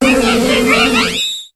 Cri d'Apireine dans Pokémon HOME.